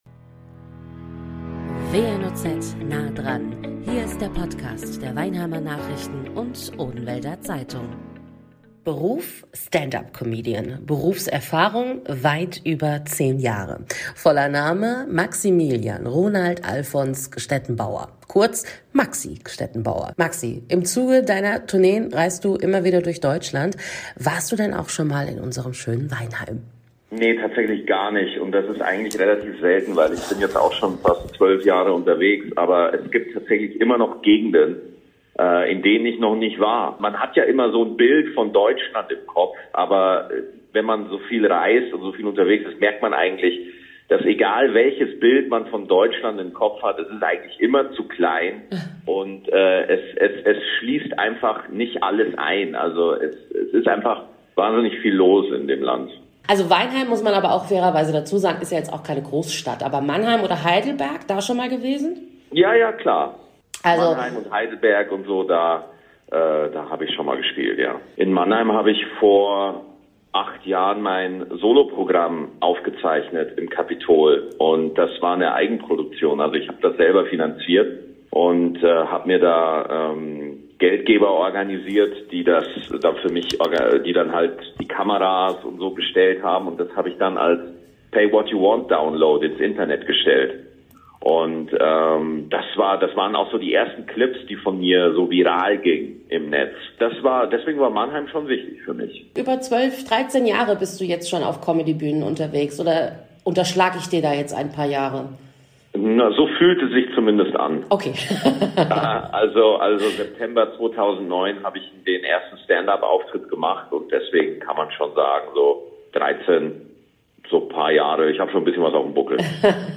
Studiogast: Stand-Up-Comedian Maxi Gstettenbauer erzählt über seinen Umgang mit der unsichtbaren Krankheit Depression und warum Comedy und Angstgefühle durchaus nebeneinander existieren können.